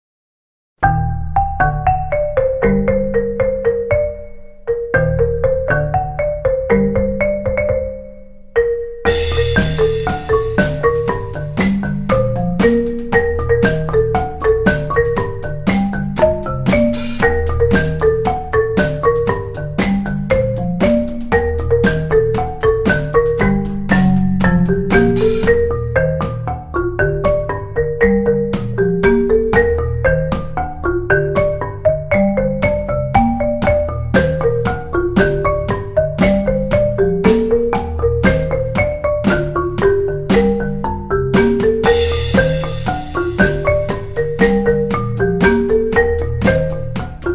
Marimba Cover gratis en su teléfono en la categoría Pop